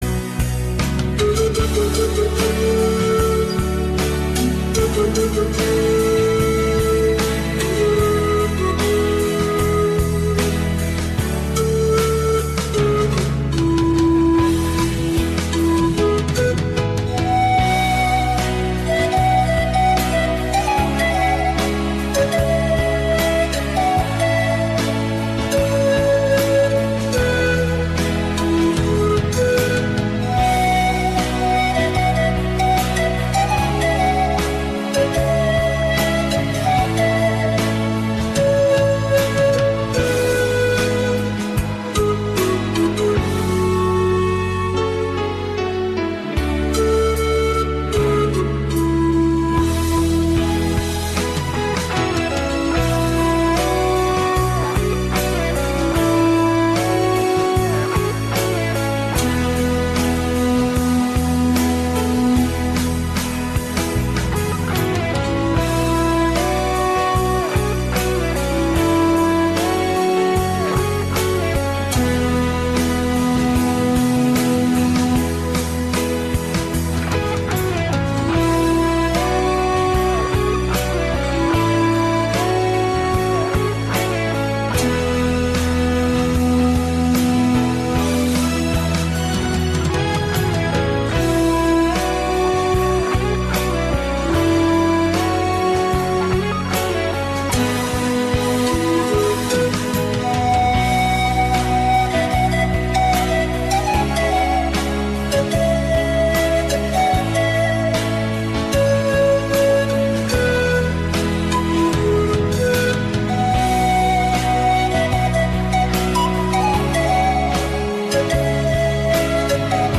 ROMÂNTICAS-INSTRUMENTAL-NA-FLAUTA-PAN.mp3